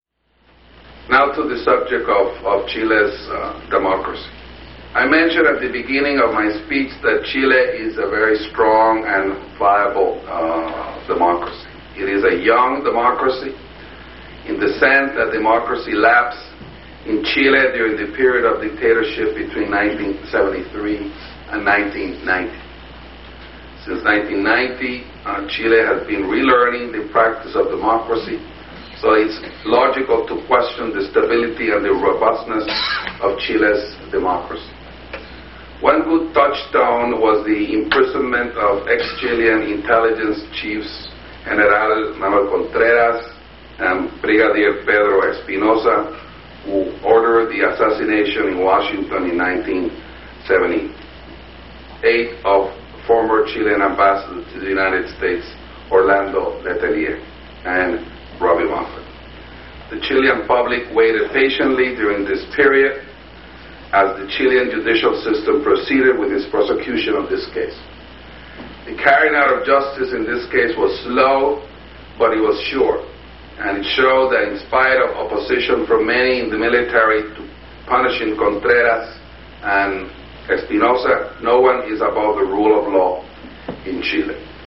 MPEG & AU Audio of the Presentation
Ambassador Gabriel Guerra-Mondragon